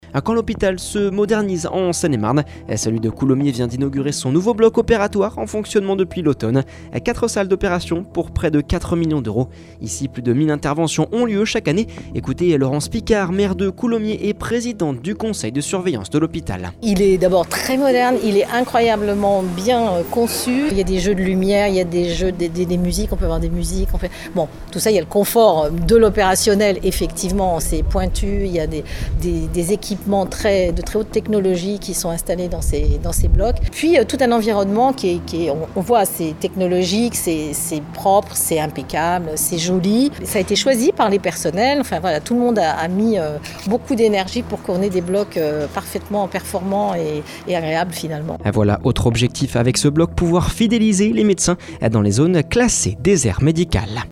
Laurence Picard, maire de Coulommiers et présidente du conseil de surveillance de l'hôpital.